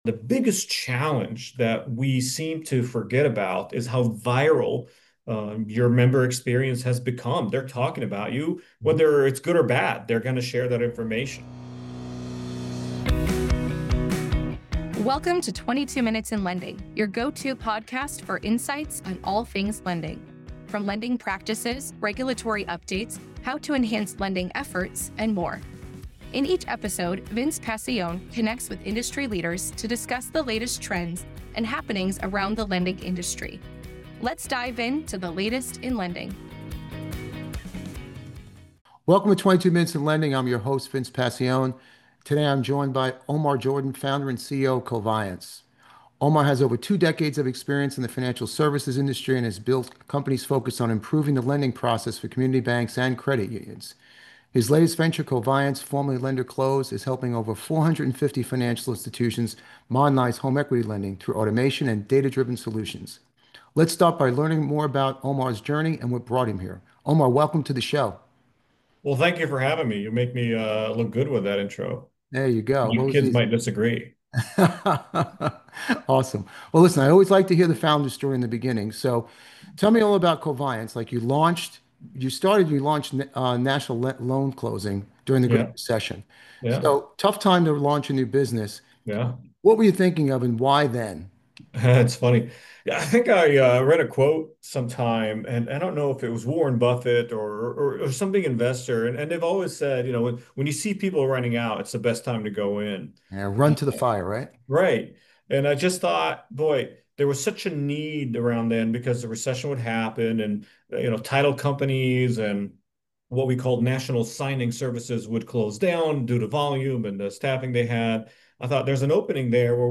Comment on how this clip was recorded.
Play Rate Listened List Bookmark Get this podcast via API From The Podcast 22 Minutes in Lending is a podcast that brings you leading conversations on lending.